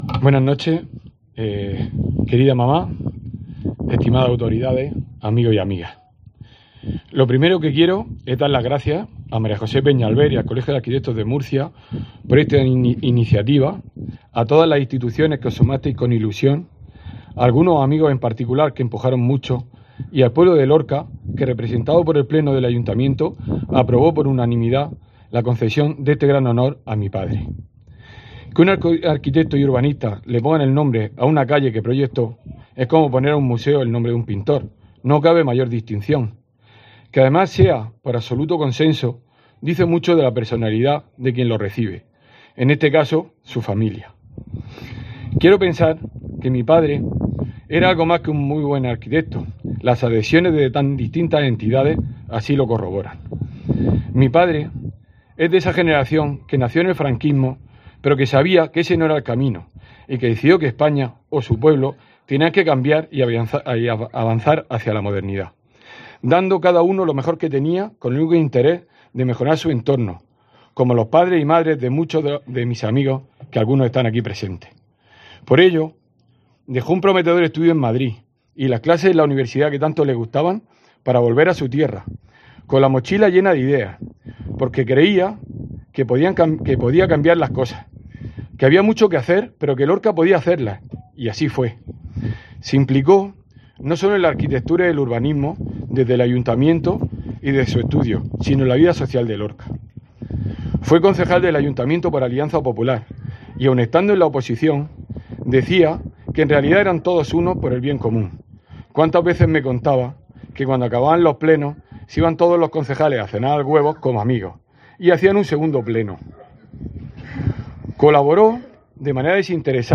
Emotivo discurso